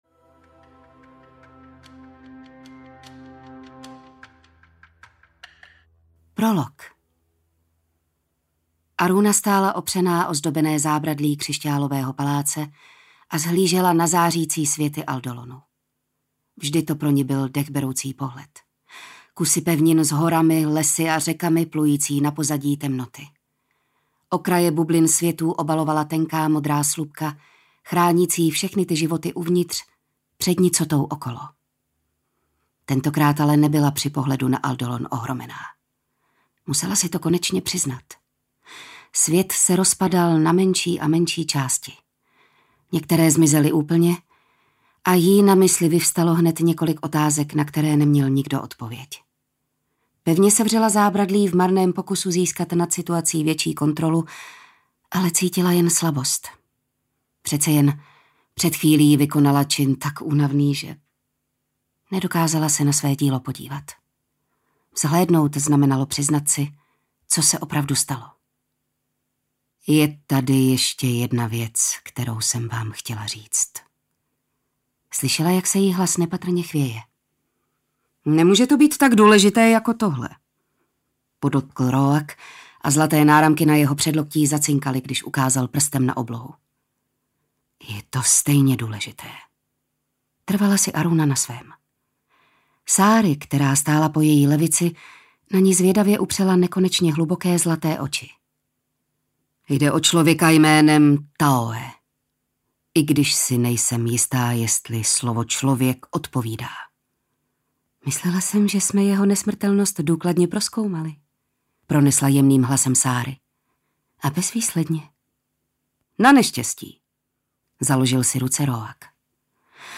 Arila: Hranice času audiokniha
Ukázka z knihy
• InterpretJitka Ježková